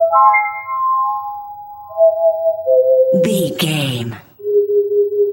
Short musical SFX for videos and games.,
Epic / Action
Fast paced
In-crescendo
Ionian/Major
aggressive
intense
energetic
funky